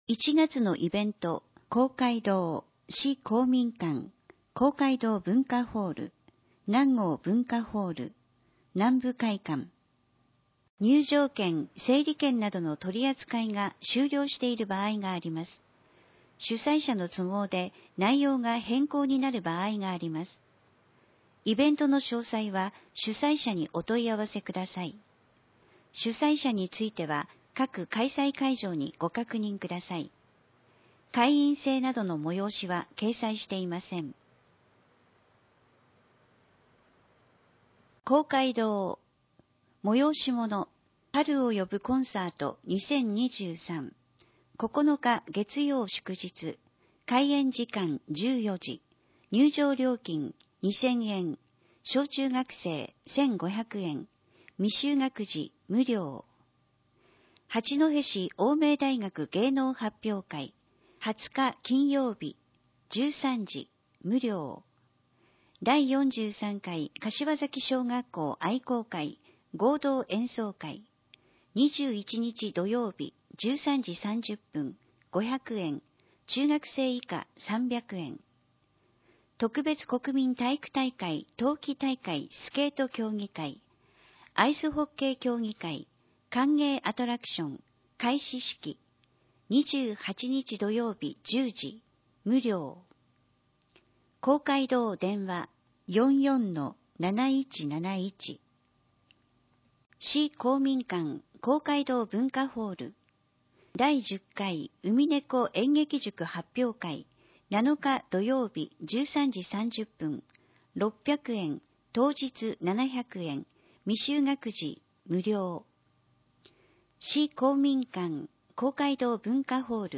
音声は、ボランティアグループ「やまびこの会」が朗読録音したものです。